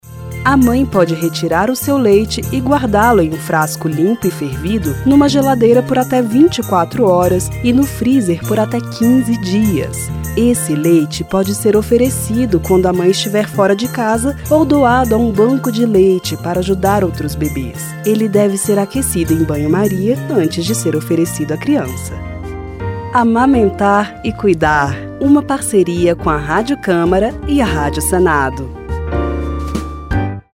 Por isso, a Rádio Câmara, em parceria com a Rádio Senado, lança a campanha “Amamentar e cuidar”. São cinco spots de 30 segundos cada.